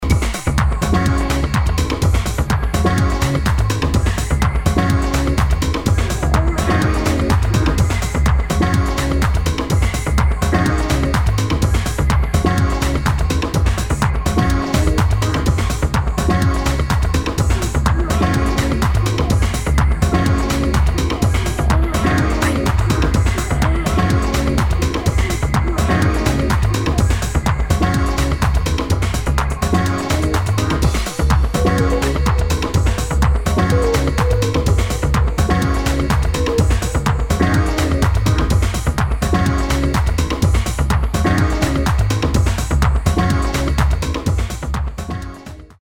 [ TECHNO / ACID / TRANCE ]